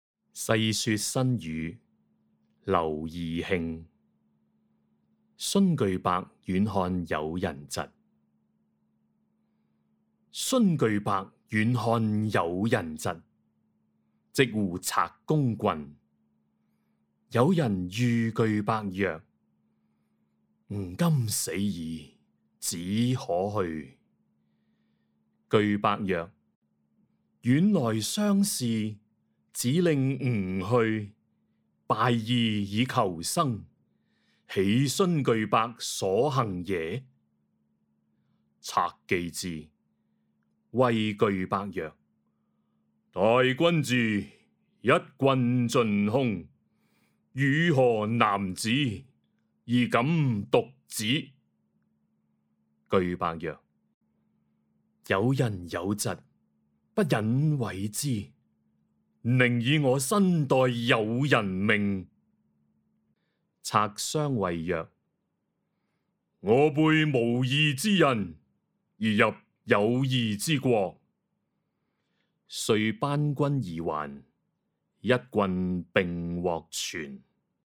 誦讀錄音
(粵語台詞誦)